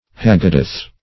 Haggadoth - definition of Haggadoth - synonyms, pronunciation, spelling from Free Dictionary
haggadoth.mp3